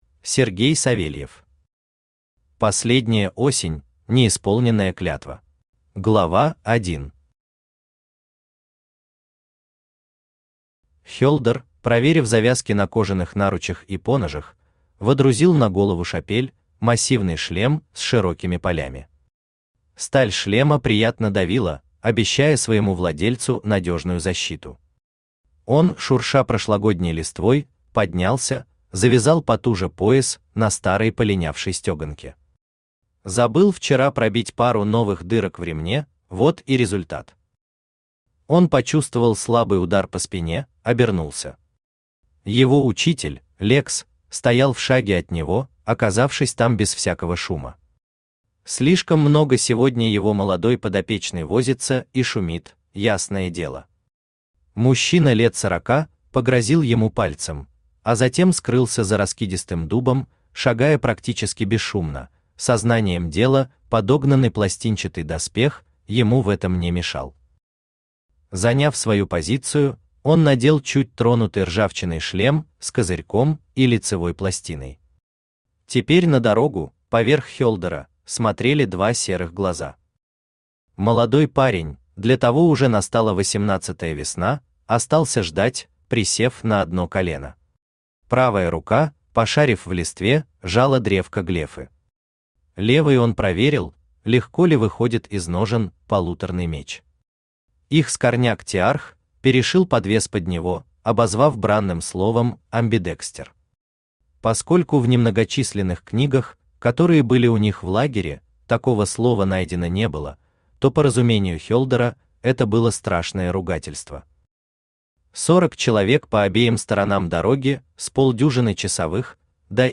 Книга I Автор Сергей Алексеевич Савельев Читает аудиокнигу Авточтец ЛитРес.